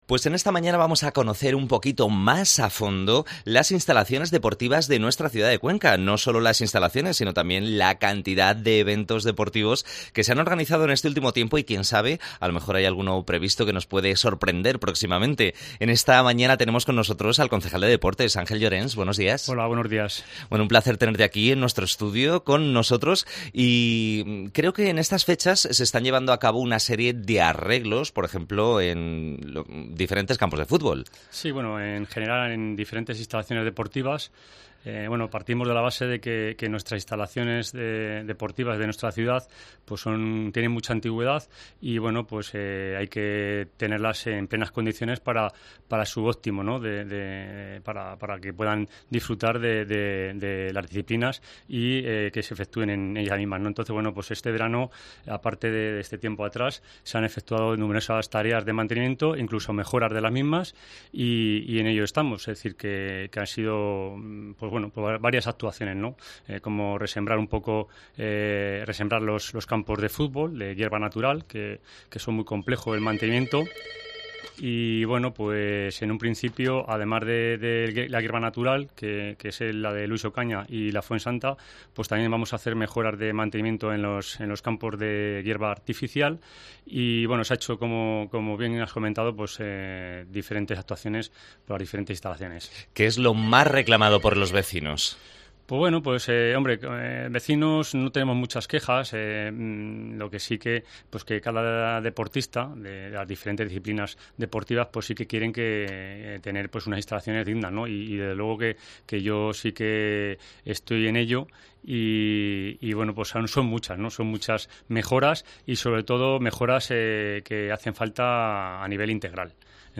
AUDIO: Entrevista al concejal de deportes Angel Llorens